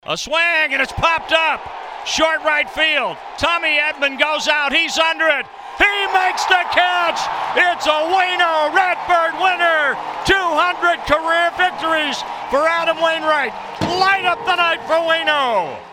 John Rooney had the call as Cardinals reliever Ryan Helsley made this pitch for the final out of the game.